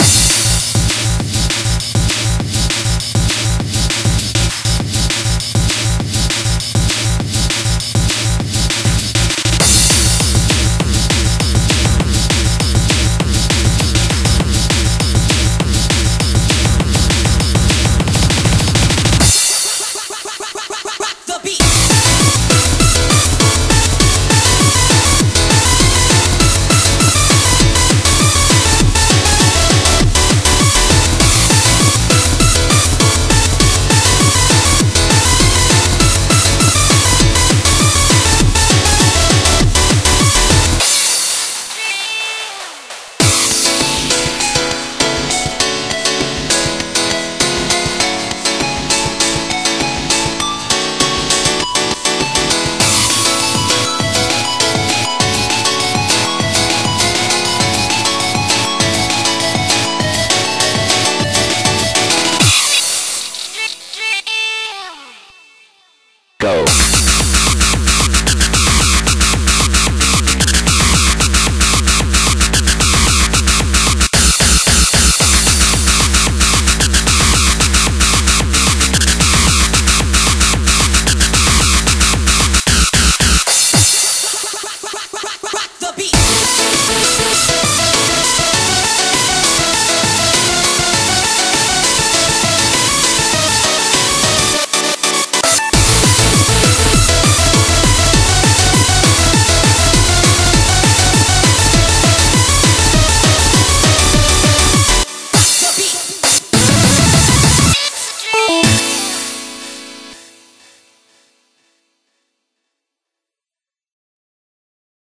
BPM100-225
Audio QualityPerfect (High Quality)